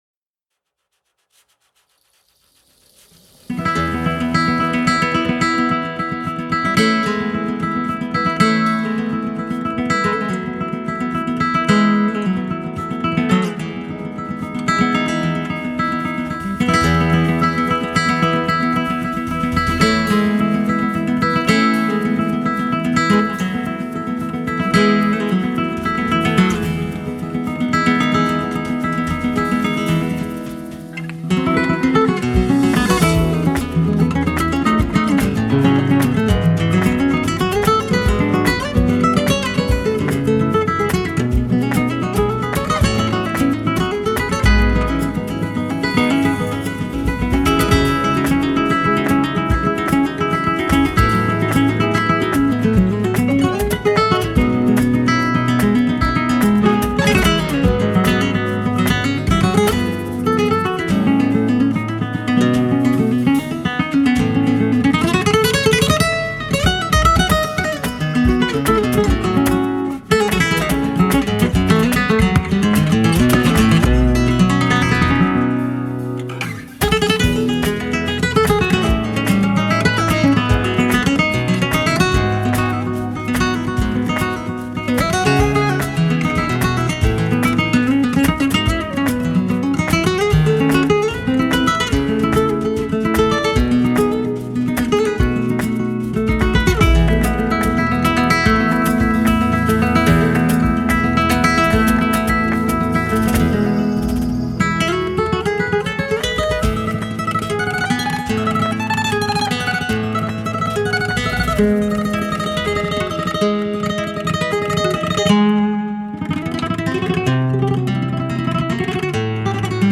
zapateado
composition et guitare
clavier
percussions